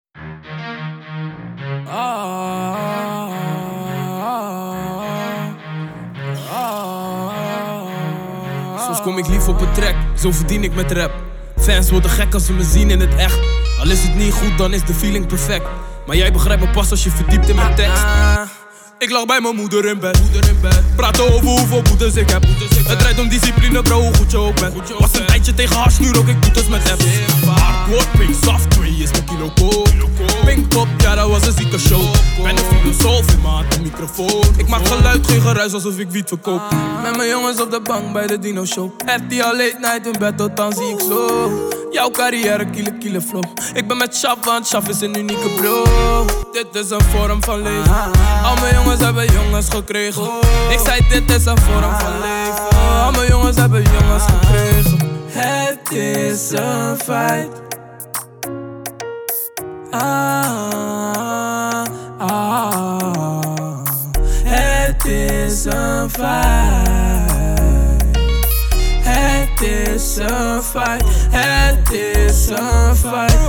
• Качество: 224, Stereo
мужской вокал
Хип-хоп
спокойные